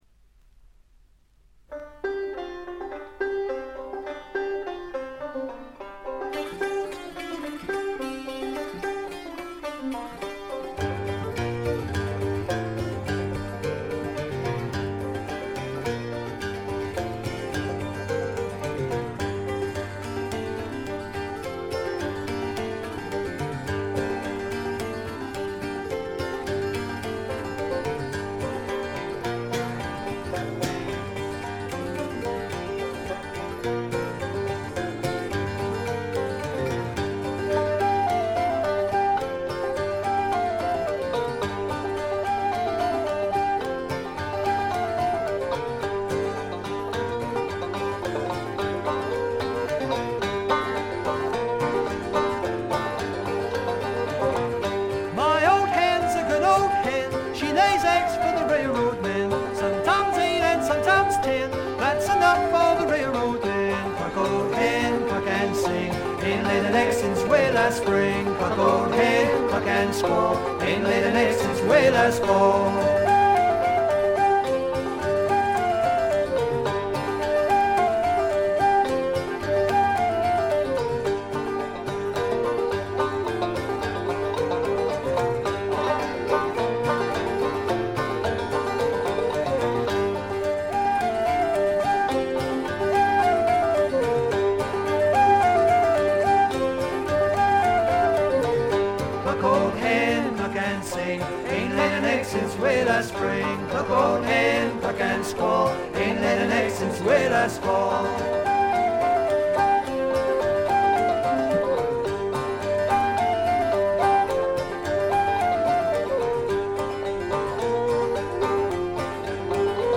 ところどころでチリプチ（特にB2は目立ちます）。鑑賞を妨げるようなノイズはありません。
試聴曲は現品からの取り込み音源です。